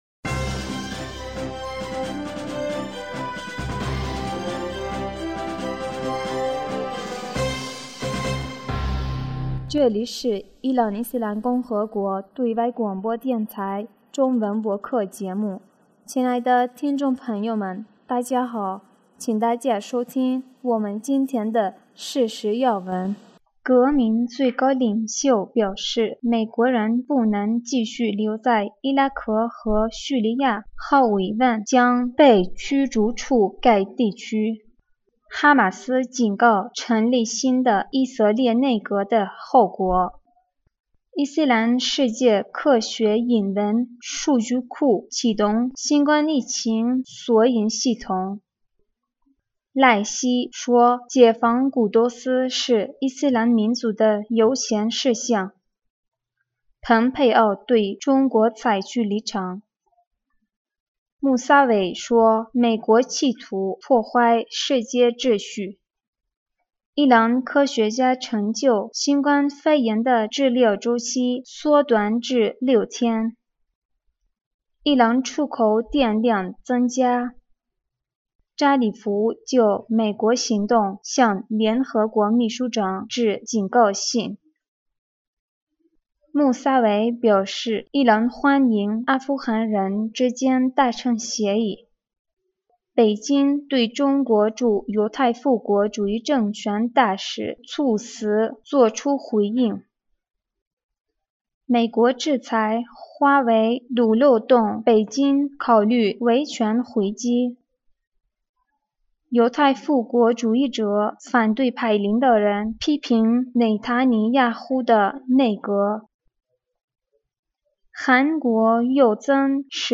2020年5月18日 新闻